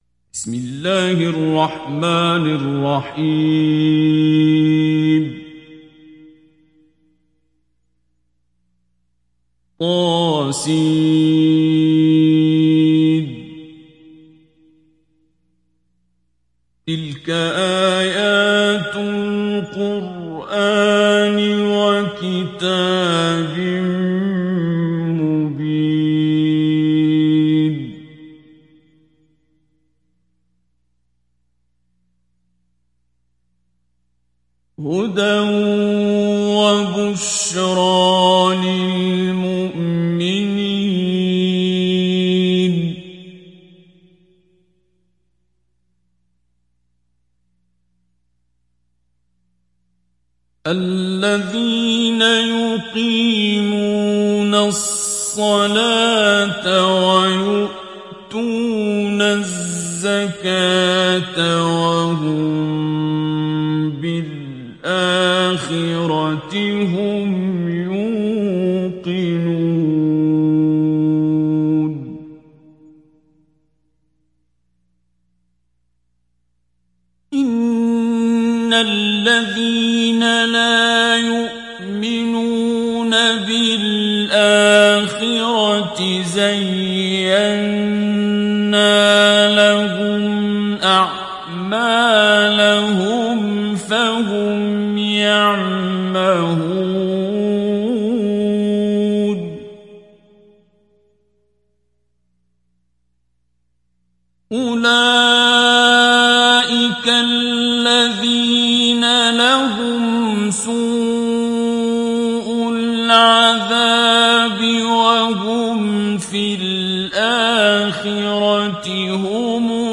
Sourate An Naml Télécharger mp3 Abdul Basit Abd Alsamad Mujawwad Riwayat Hafs an Assim, Téléchargez le Coran et écoutez les liens directs complets mp3
Télécharger Sourate An Naml Abdul Basit Abd Alsamad Mujawwad